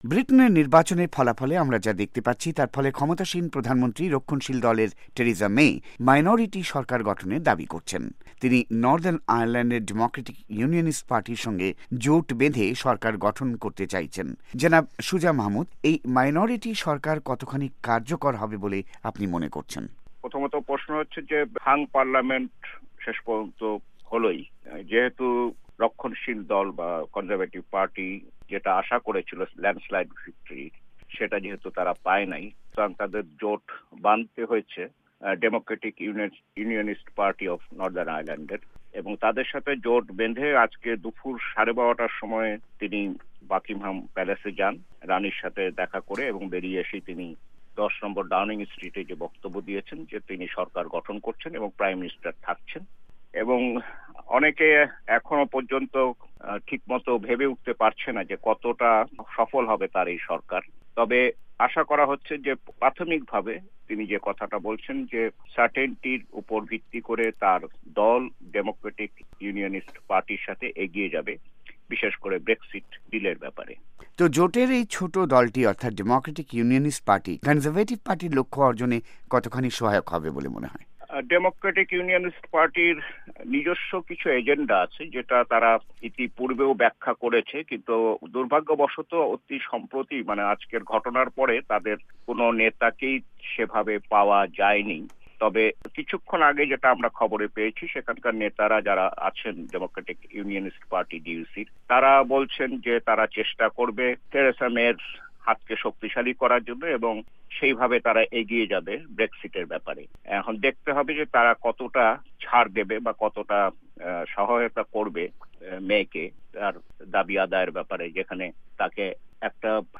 ওয়াশিংটন স্টুডিও থেকে টেলিফোনে সাক্ষাৎকারটি নিয়েছেন